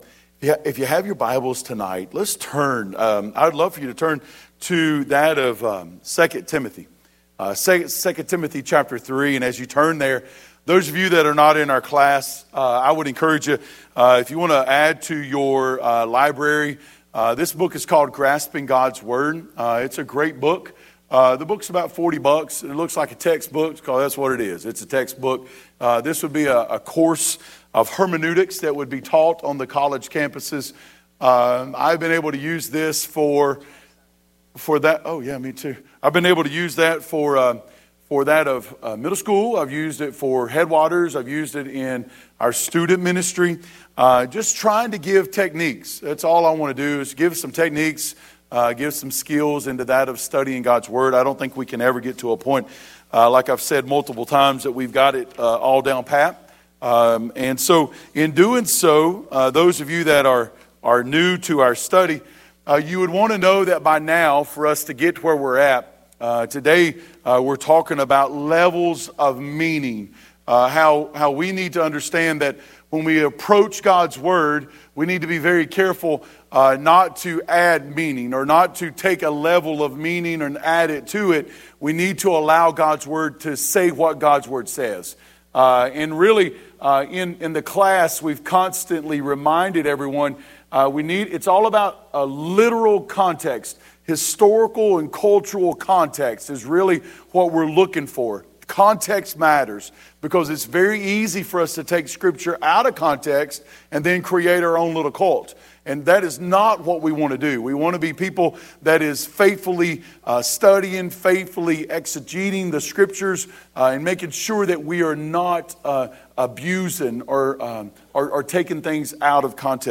Sunday Evening Service Service Type: Sunday Evening Worship Share this